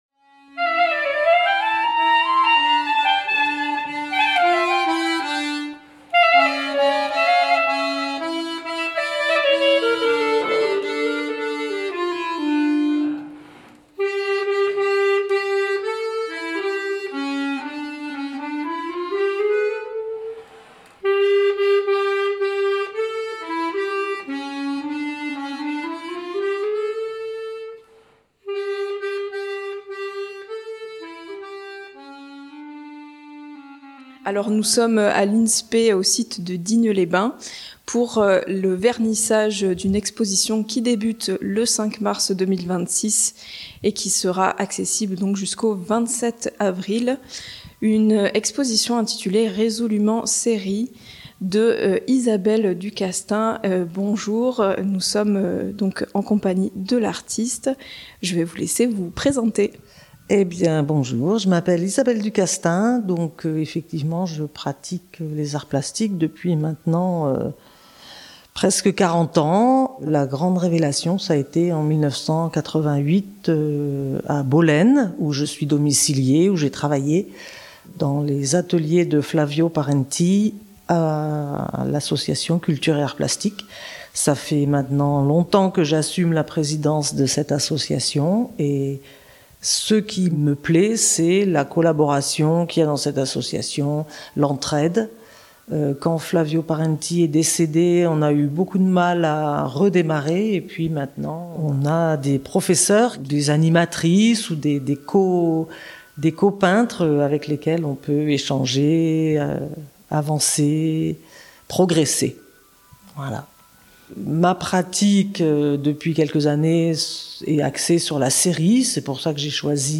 Vernissage de l'exposition "Résolument séries"